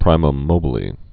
(prīməm mōbə-lē, prēməm mōbĭ-lā)